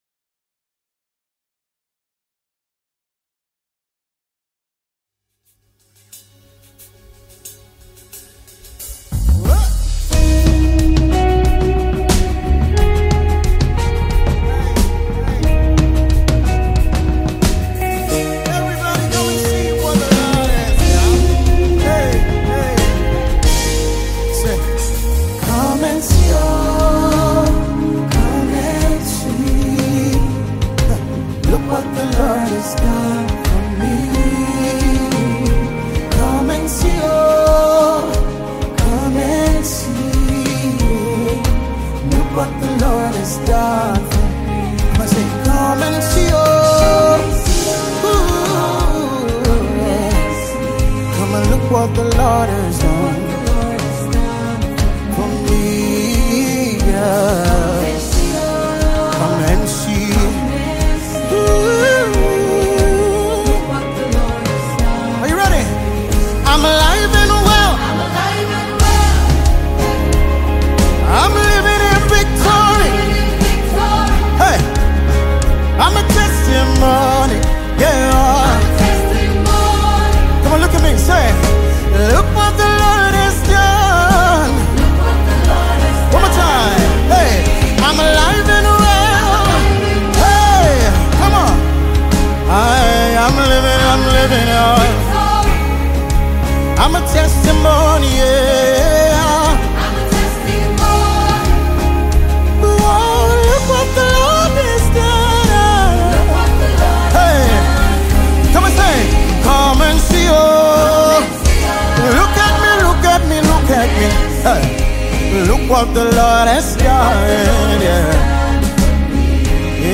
live recording project